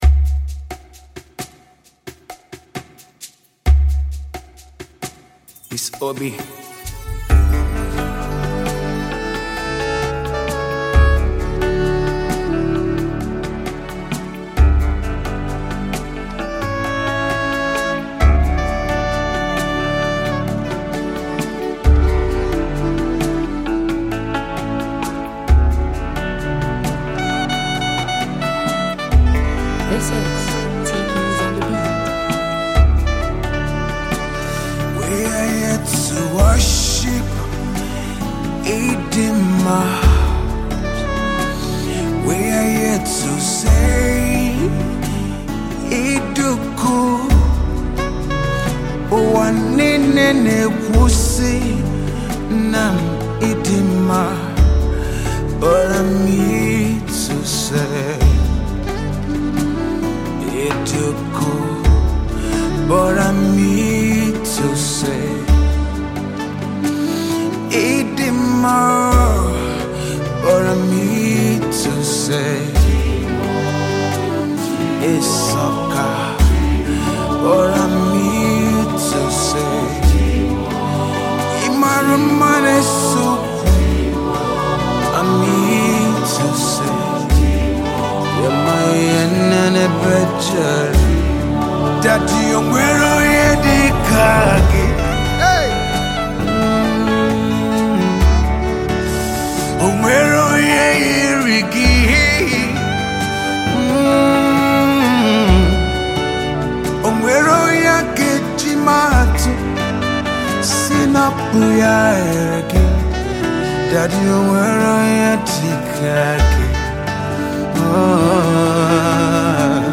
praise and worship